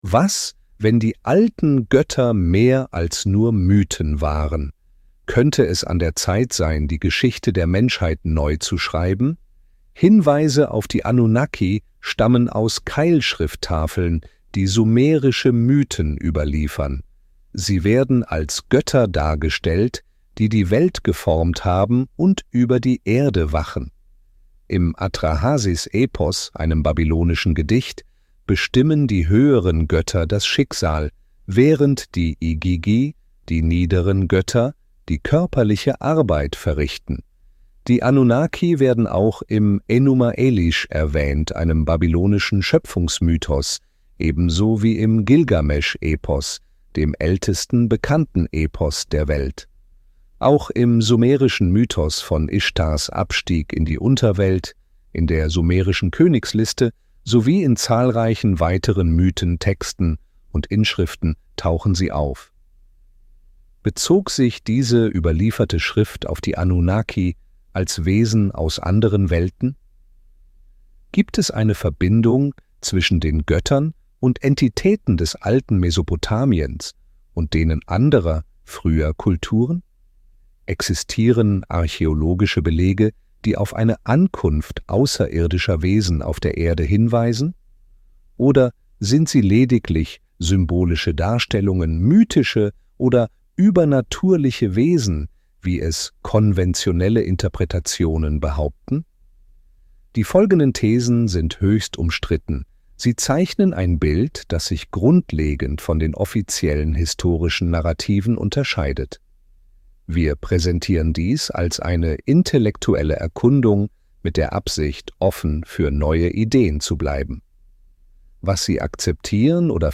Anunnaki-Träume - Der wahre Ursprung der Menschheit flüstert dir zu (Hörbuch)